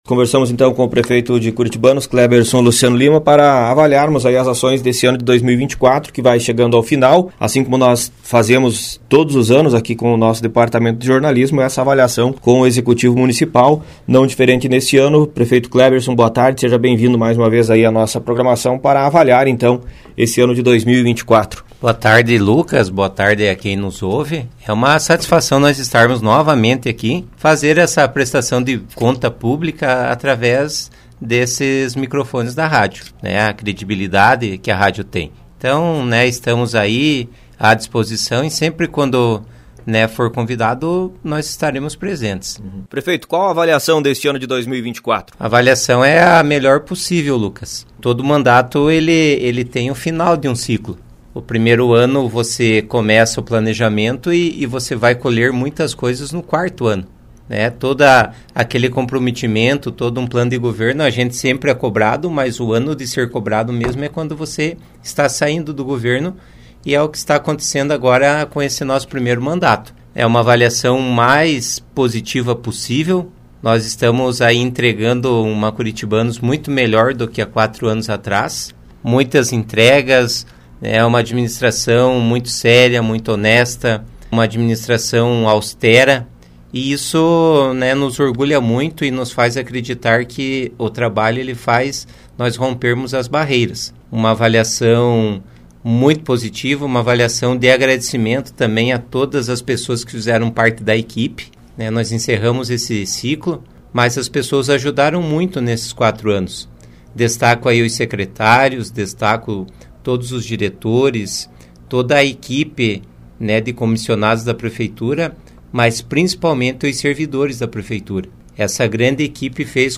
ENTREVISTA: Prefeito Kleberson Lima avalia primeiro mandato